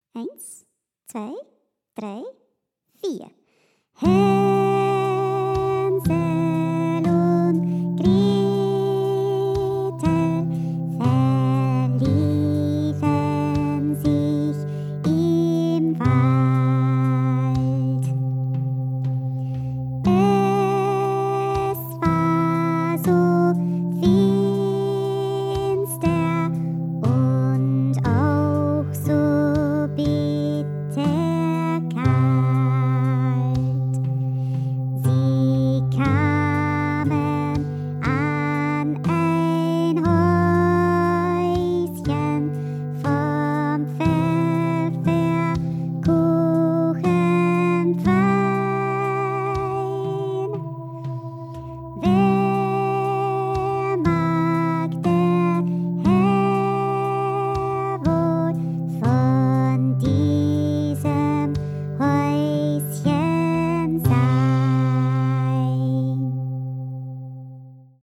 Mit Gesang